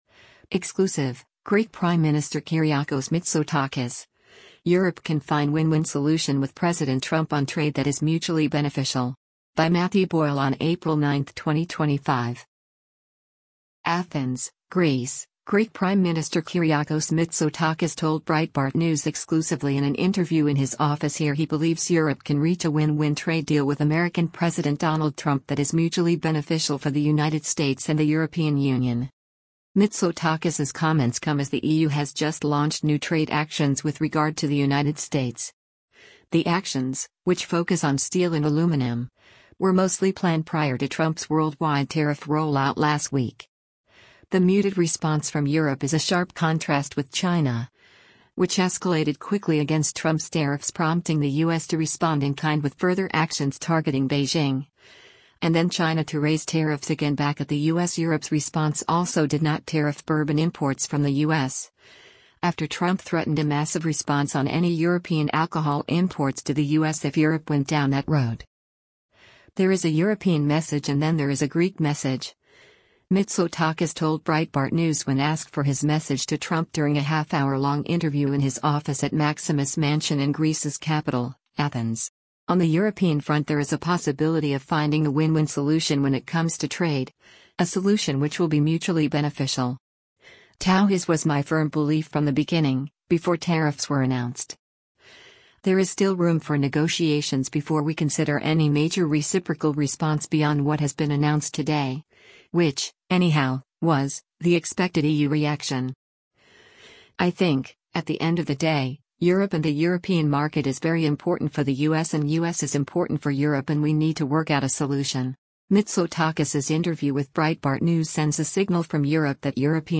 ATHENS, Greece — Greek Prime Minister Kyriakos Mitsotakis told Breitbart News exclusively in an interview in his office here he believes Europe can reach a “win-win” trade deal with American President Donald Trump that is “mutually beneficial” for the United States and the European Union.